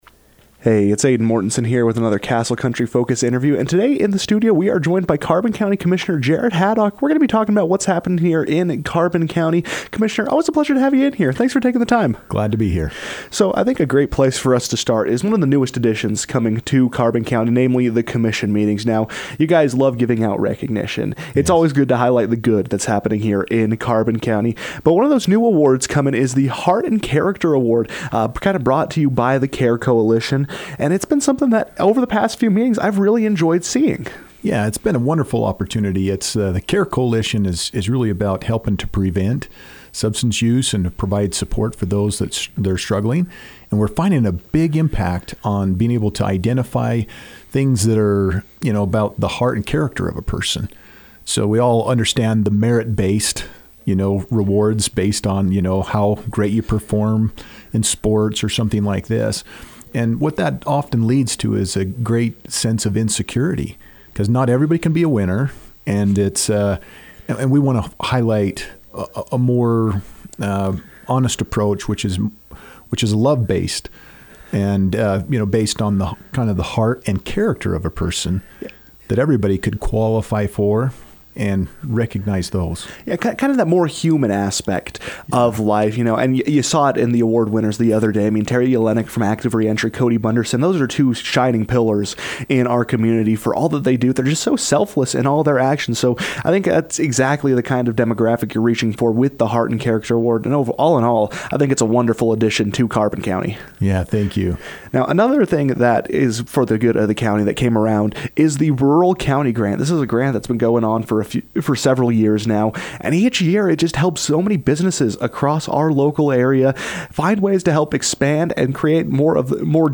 Joining the KOAL newsroom, Carbon County Commissioner Jared Haddock spoke on a variety of topics and affairs, including the Heart and Character award.